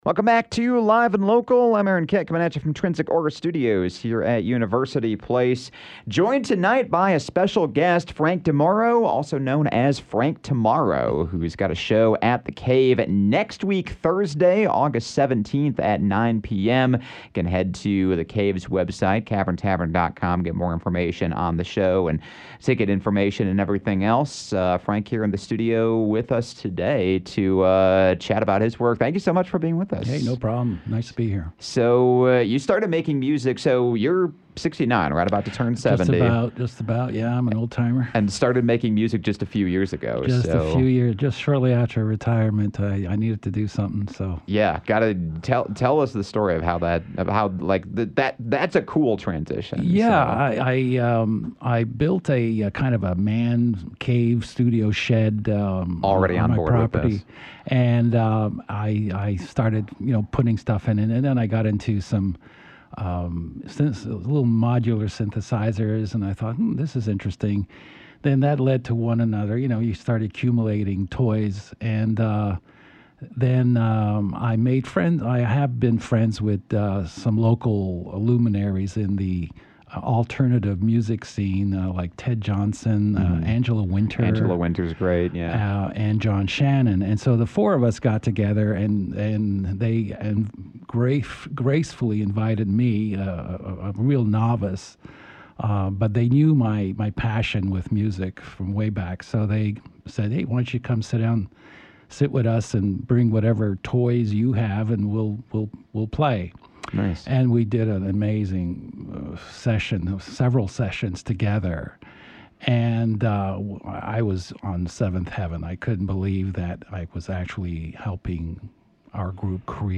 Experimental electronic artist